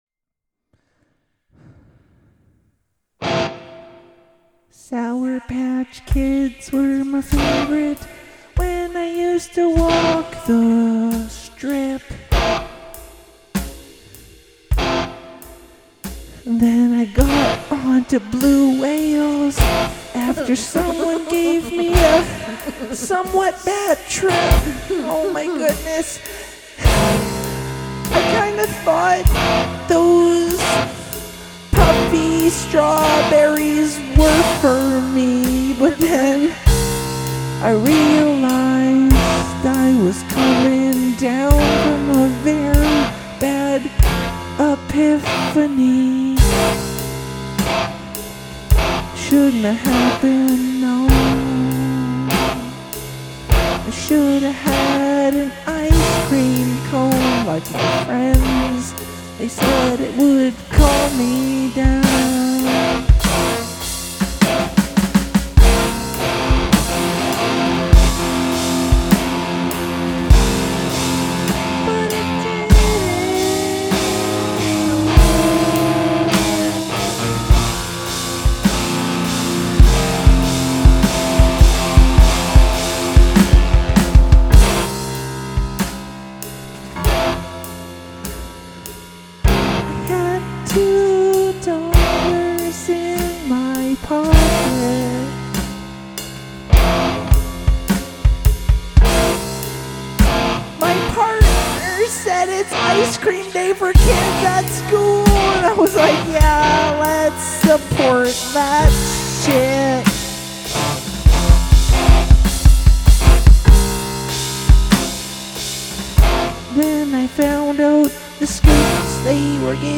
Experimental Rock